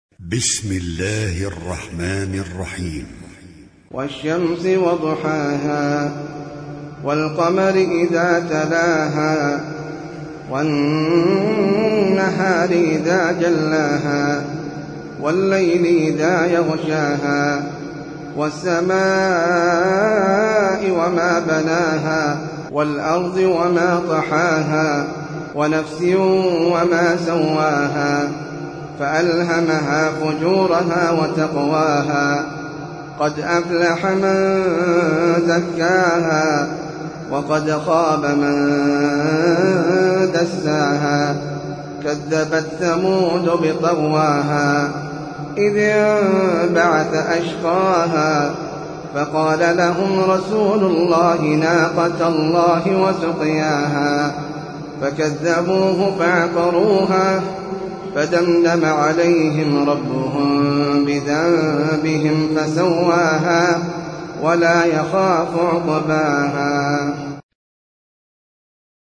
سورة الشمس - المصحف المرتل (برواية حفص عن عاصم)
جودة عالية